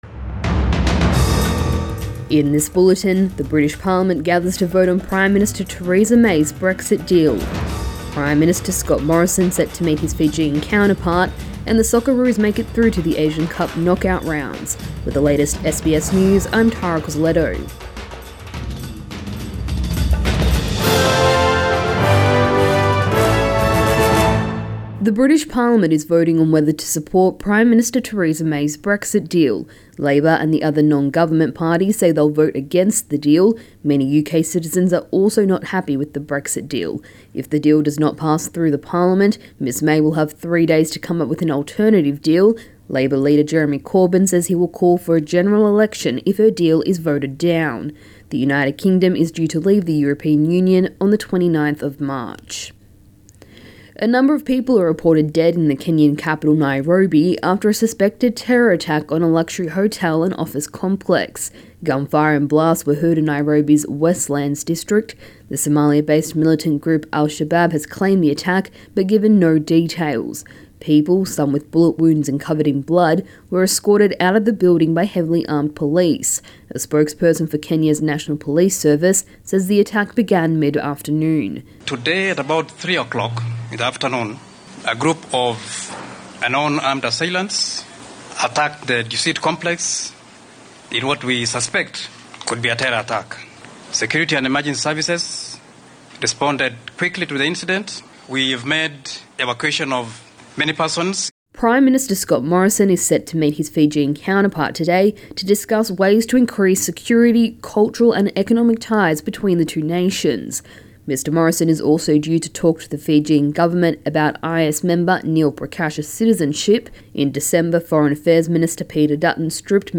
AM bulletin 16 January